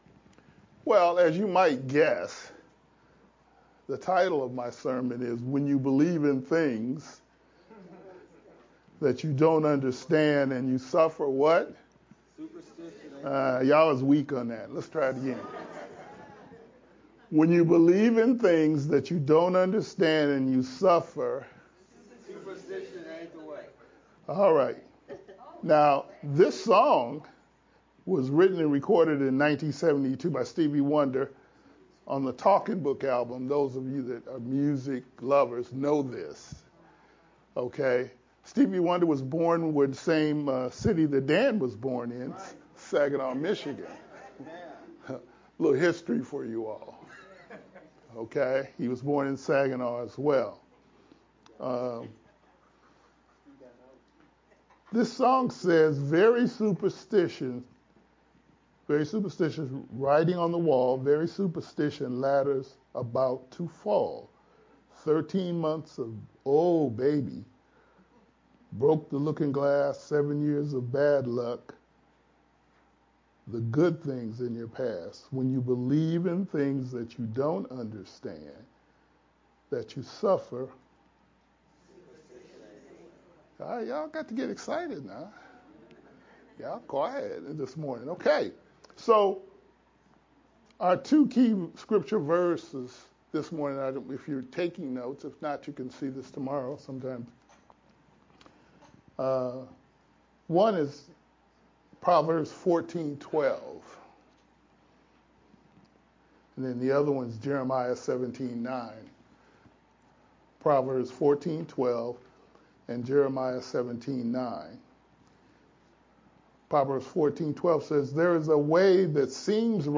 Aug-3rd-VBCC-edited-sermon-only-Made-with-Clipchamp_Converted-CD.mp3